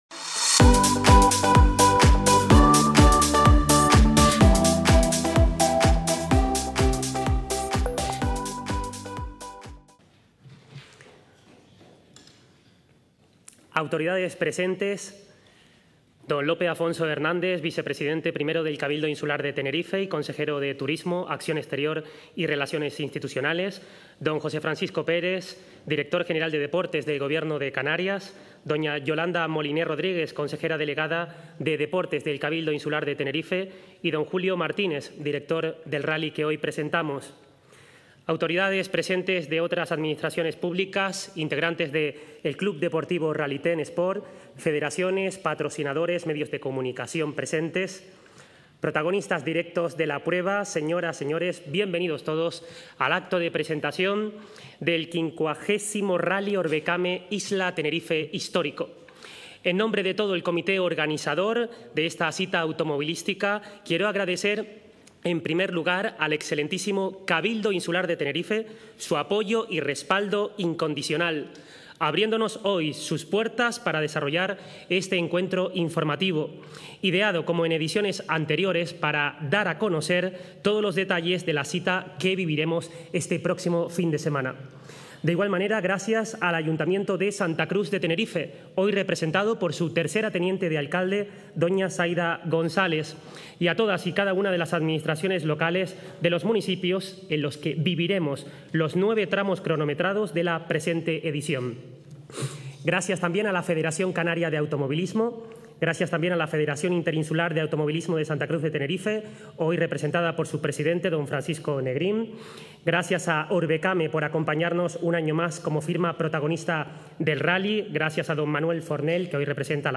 El 50º Rallye Orvecame Isla Tenerife Histórico, presentado hoy, 10 de septiembre, en el Salón Noble del Cabildo de Tenerife, contará con un bloque de 71 participantes que tomarán la salida en las diferentes categorías previstas: 36 equipos en...